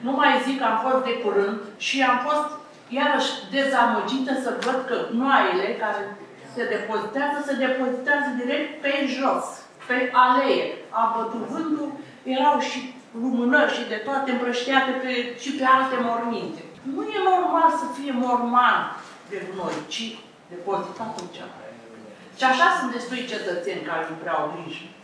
Azi, 27 februarie 2025, Consiliul Local Tulcea s-a reunit într-o nouă ședință ordinară pentru a discuta o serie de proiecte ce vizează, printre altele, administrarea domeniului public, cofinanțarea serviciilor sociale destinate persoanelor fără adăpost și măsuri fiscale pentru contribuabilii aflați în dificultate.
Un alt punct sensibil a fost curățenia din cimitire, unde Tatiana Haliț a semnalat problema gestionării deșeurilor.